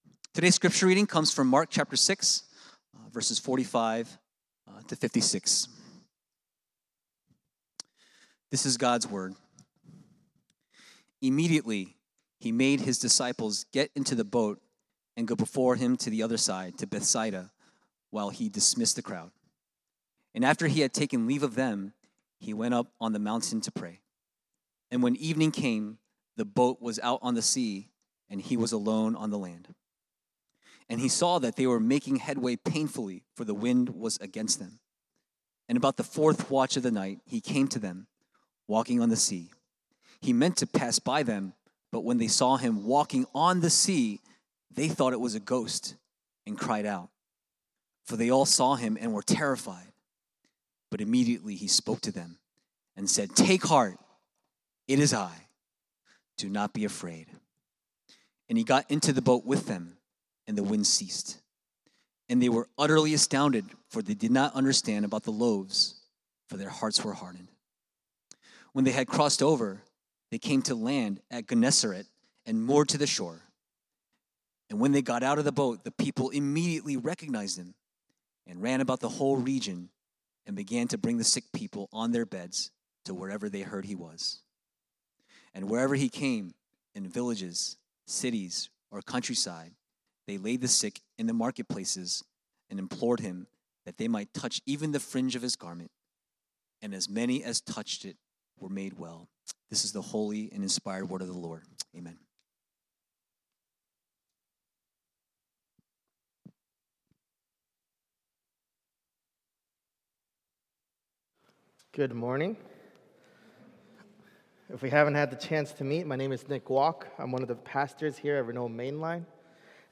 Weekly Sermons from Renewal Main Line